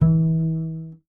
samples / pluck / BS D#3 PI.wav
BS D#3 PI.wav